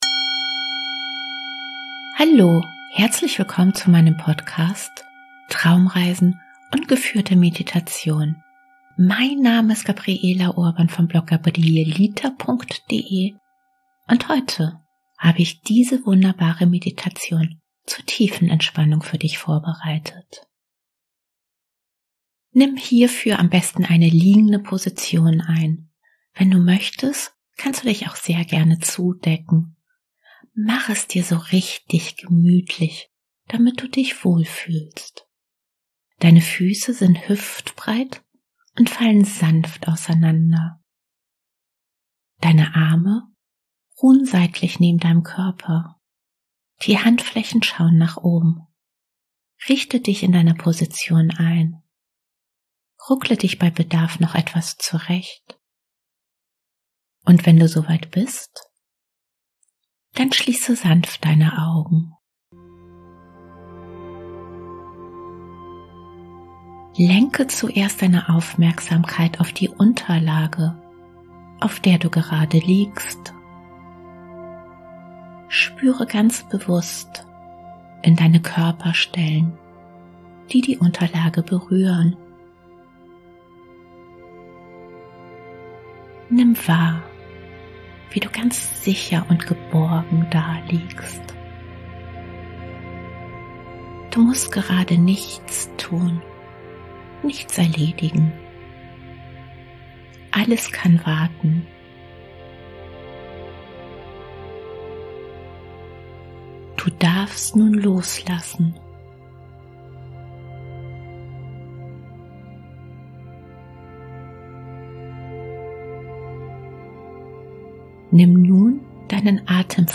Heute habe ich diese Meditation Tiefenentspannung 10 Minuten für dich vorbereitet. Nimm für diese geführte Meditation zum Entspannen am besten eine liegende Position ein.
Und wenn du soweit bist, dann lass dich von meiner Stimme in eine wunderbare Tiefenentspannung führen.